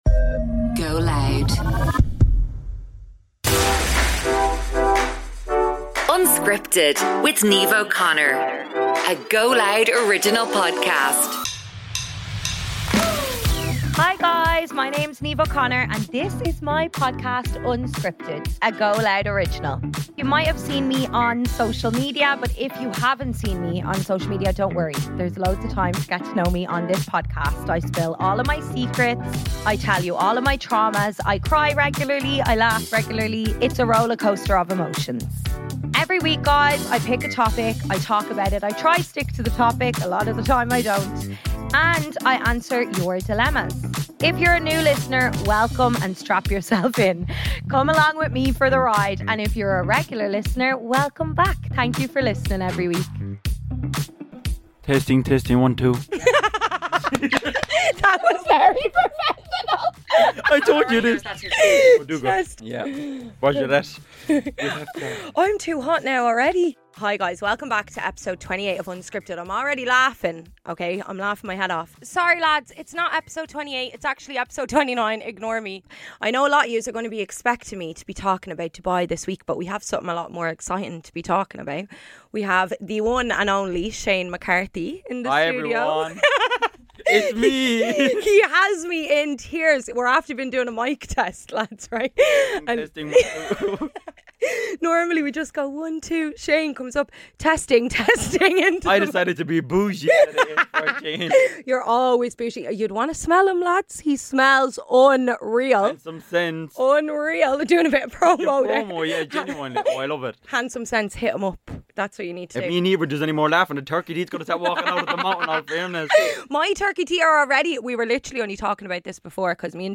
(with a few questionable impressions included)